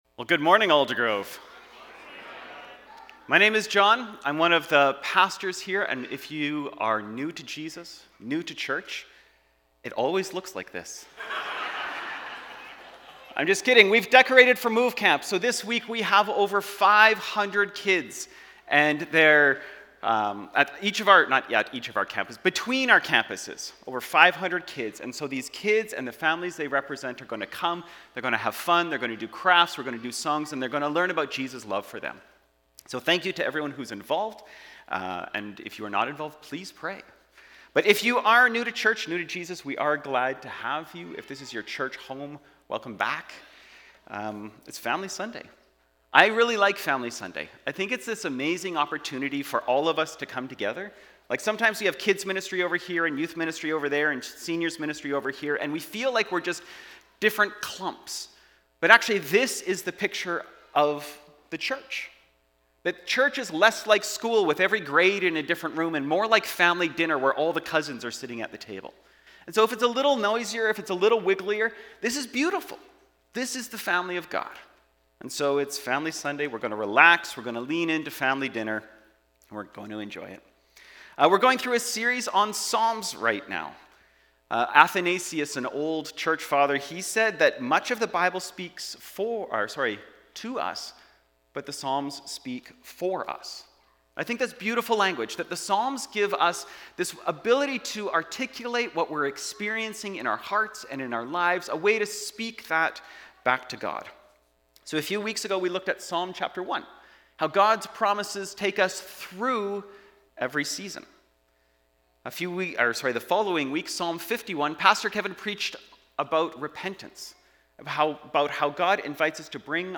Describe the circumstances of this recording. It’s a Family Sunday! Kids and adults were together in the service this week and we looked at Psalm 100, a season of joy. Joy is something we can have in every season, not because everything in our life is good but because God is good.